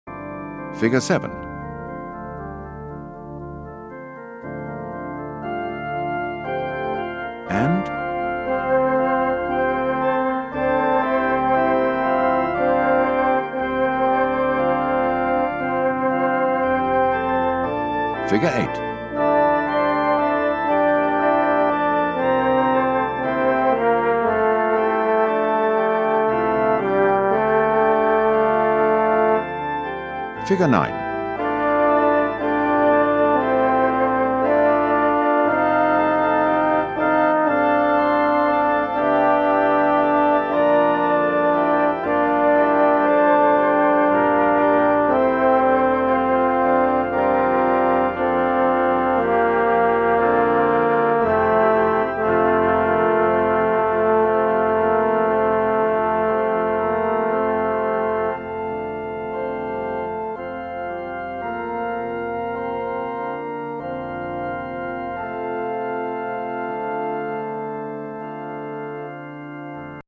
There is no text, just your part.
1st Tenor